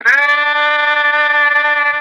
Tidyup Scream